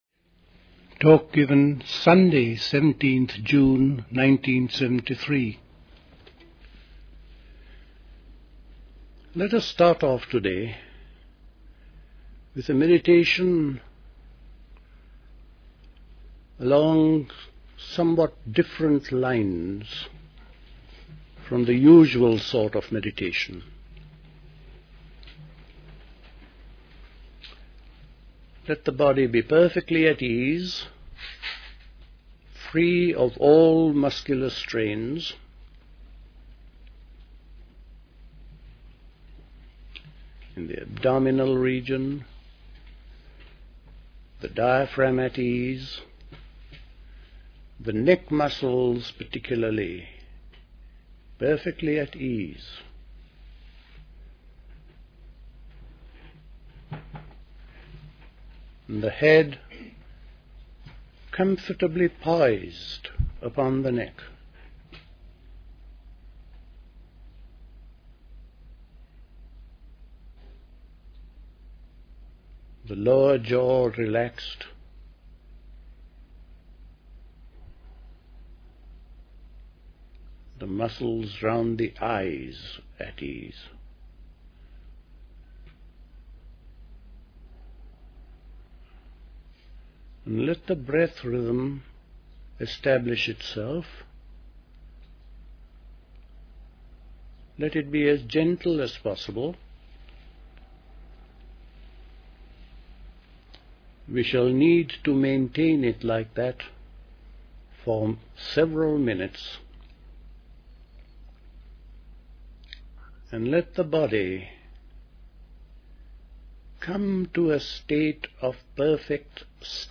A talk
at Dilkusha, Forest Hill, London on 17th June 1973